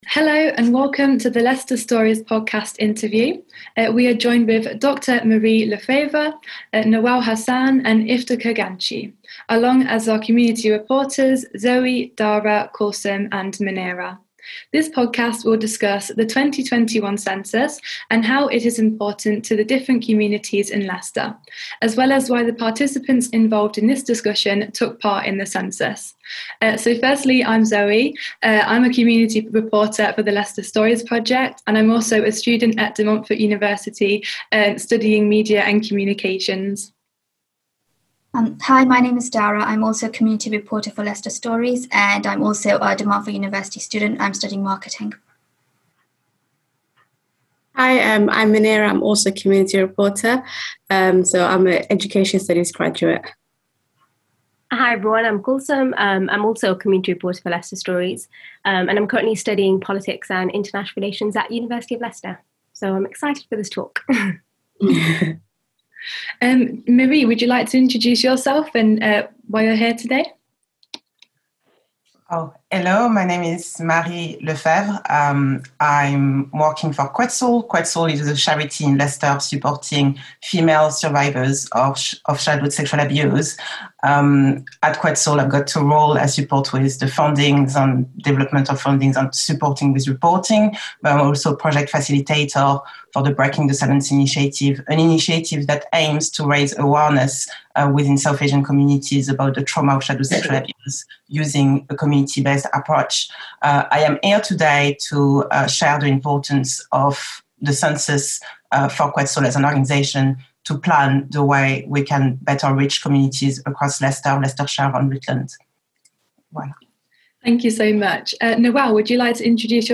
Leicester Census Stories Discussion